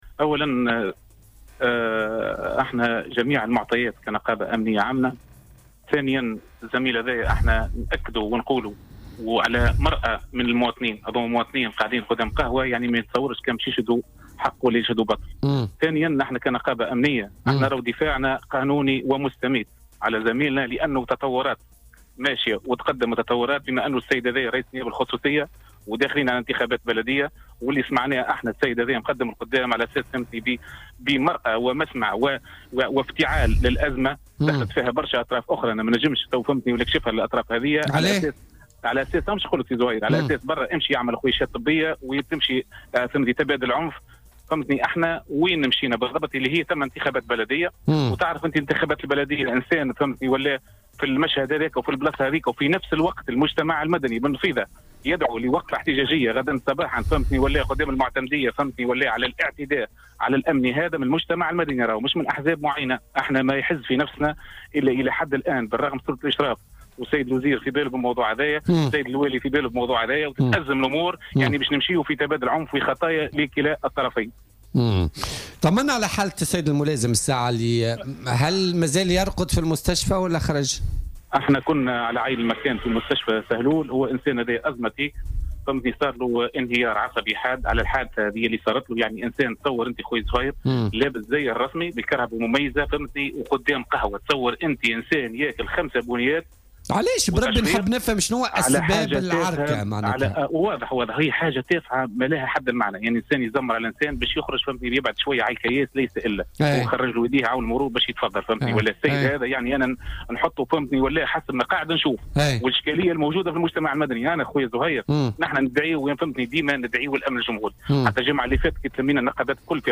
وأضاف خلال مداخلة له في برنامج "بوليتيكا" أن النقابة قررت تنظيم وقفة احتجاجية يوم الثلاثاء القادم أمام مقر ولاية سوسة، احتجاجا على الاعتداء على الأمنيين ومطالبة بتطبيق القانون.